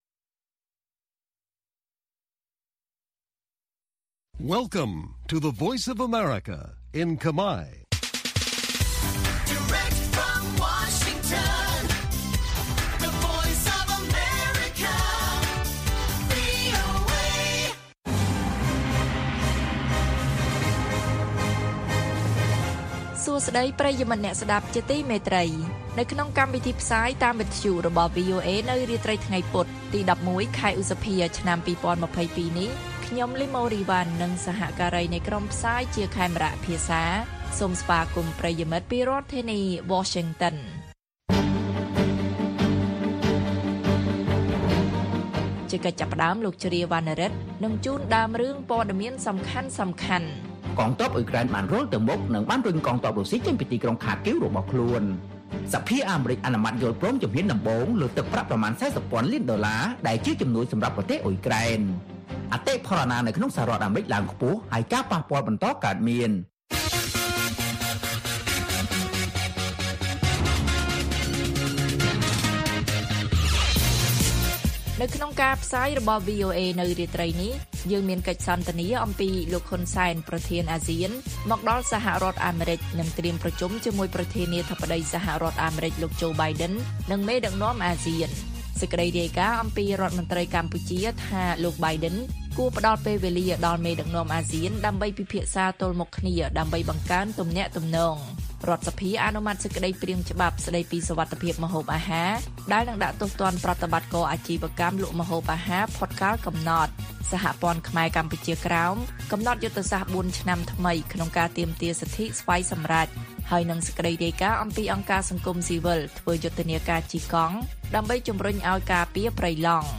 ព័ត៌មានពេលរាត្រី ១១ ឧសភា៖ កិច្ចសន្ទនាអំពីលោក ហ៊ុន សែន ប្រធានអាស៊ាន មកដល់អាមេរិកនិងត្រៀមប្រជុំជាមួយលោក Biden និងមេដឹកនាំអាស៊ាន